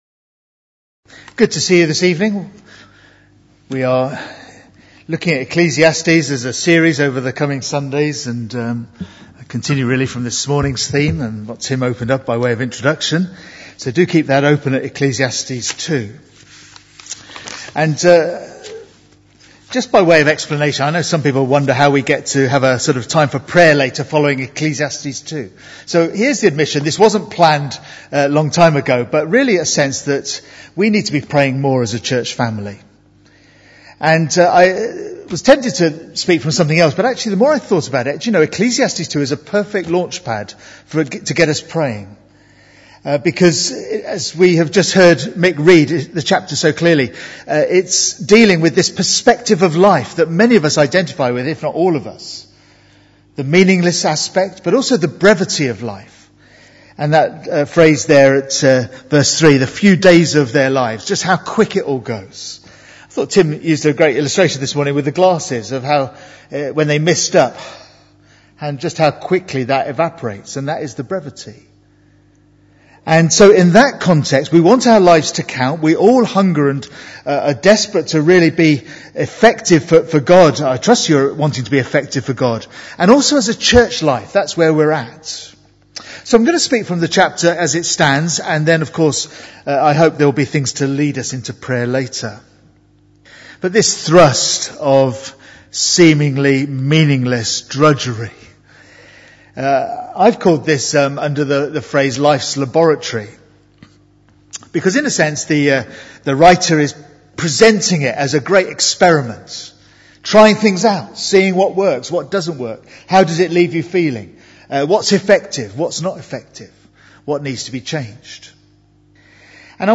- Cambray Baptist Church